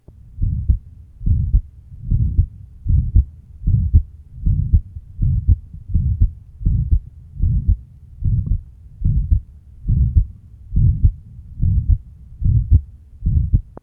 Date 1972 Type Systolic Abnormality Aortic Stenosis Post-op AS (operateed 1965) with sigfnificant residual stenosis, 15 year old To listen, click on the link below.
RUSB (Channel A) LUSB (Channel B) LLSB (Channel C) Apex (Channel D) %s1 / %s2